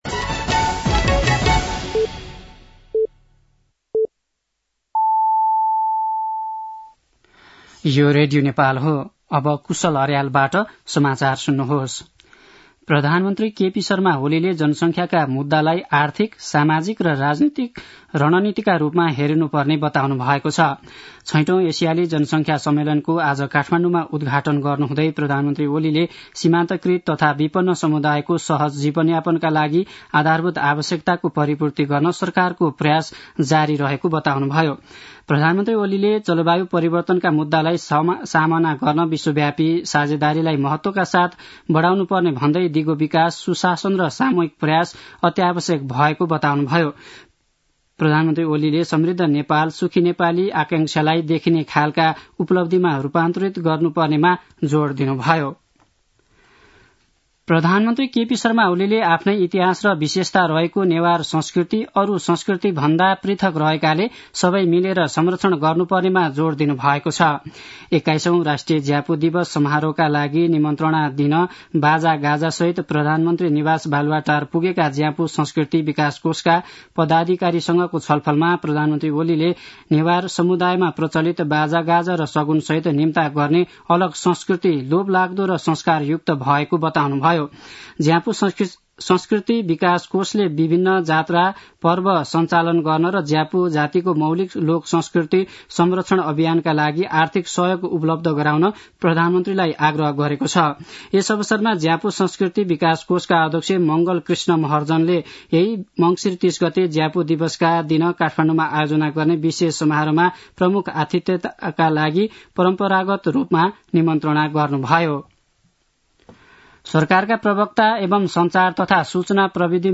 दिउँसो ४ बजेको नेपाली समाचार : १३ मंसिर , २०८१
4-pm-nepali-news-1-6.mp3